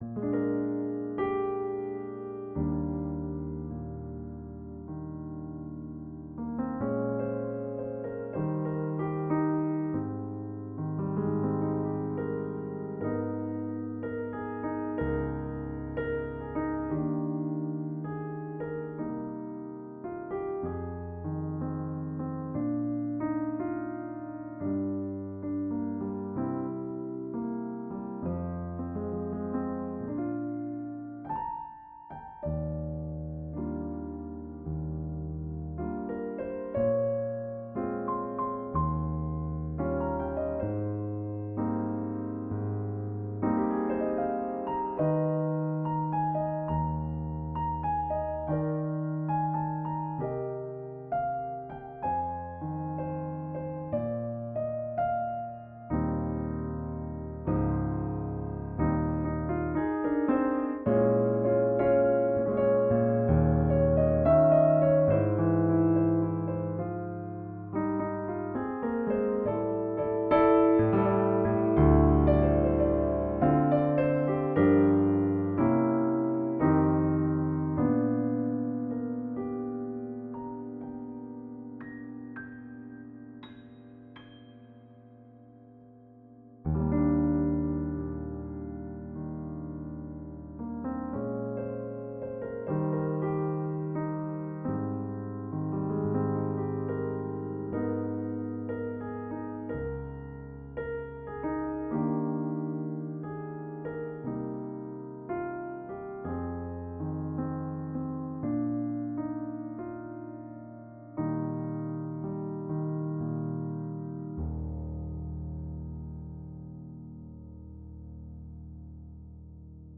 Including a recording of Misty where I play the head-solo-head format. I don’t have a set way of playing the song and much of it was “on the spot” so excuse the mistakes and pauses every now and then, oh and the background noise.